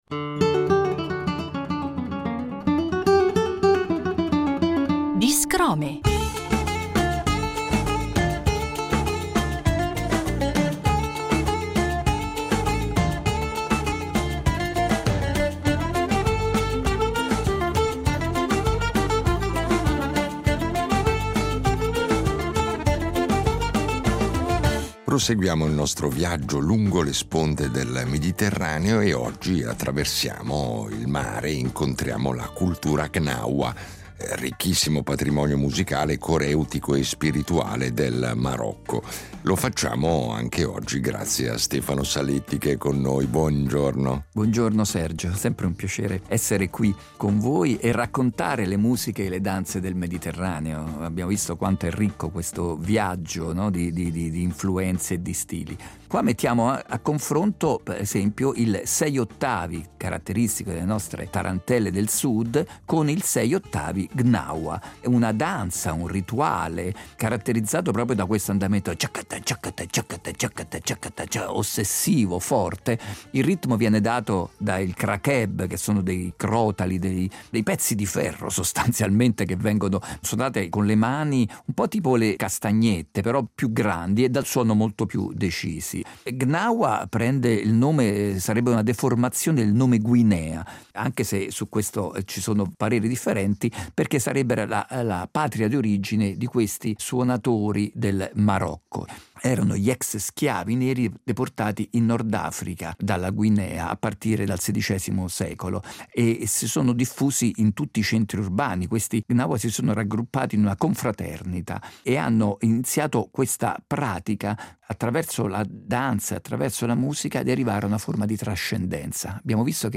Marocco-Gnawa